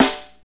Index of /90_sSampleCDs/Northstar - Drumscapes Roland/DRM_Funk/SNR_Funk Snaresx
SNR FNK S00L.wav